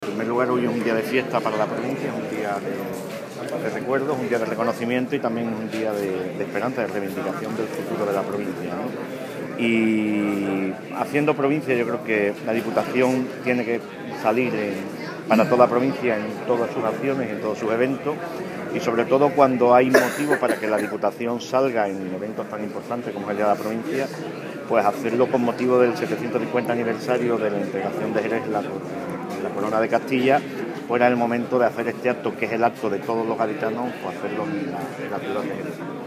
La entrega de estas distinciones se ha celebrado en el complejo de La Atalaya donde residen los museos del Vino (el Misterio de Jerez) y de los relojes (el Palacio del Tiempo). El acto institucional ha estado presidido por el titular de Diputación, José Loaiza.
Loaiza_ciudad_Jerez.mp3